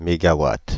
Ääntäminen
Paris: IPA: [me.ɡa.wat]